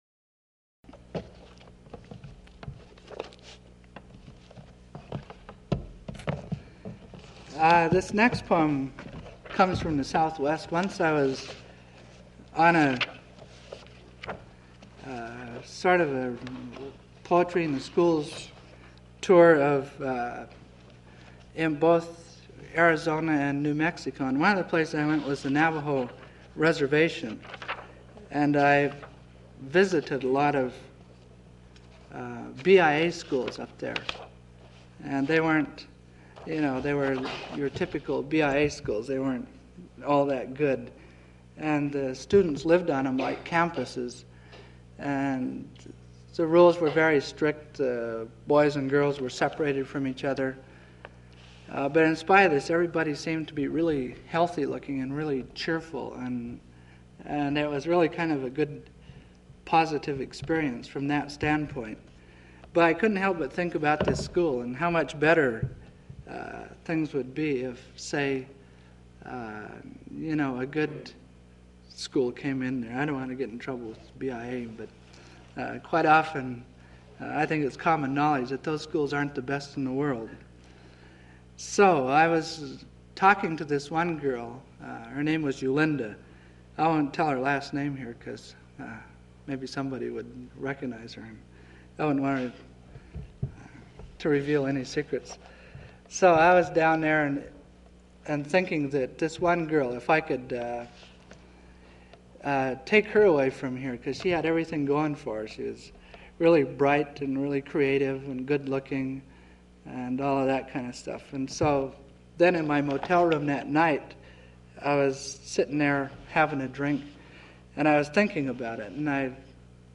James Welch reads from his poetry collection Riding the Earthboy 40 (1976) and his novel Winter in the Blood (1974).